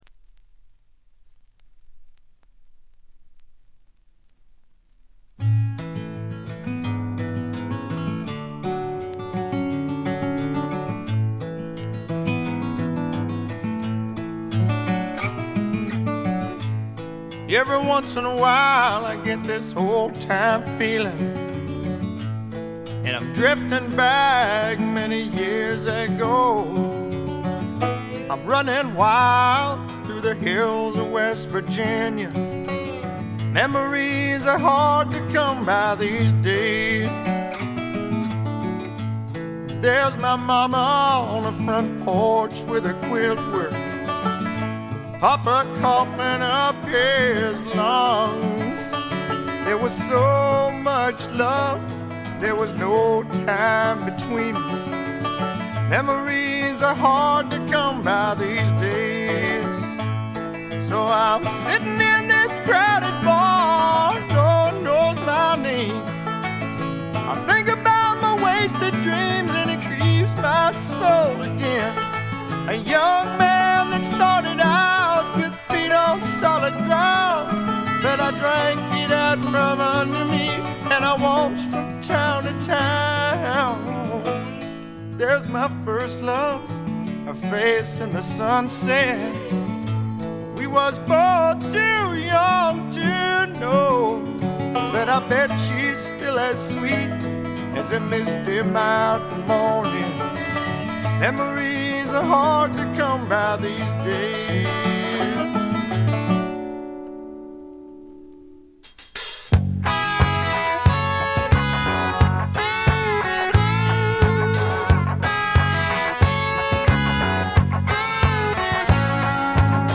ホーム > レコード：米国 スワンプ
部分試聴ですが、微細なノイズ感のみ。
試聴曲は現品からの取り込み音源です。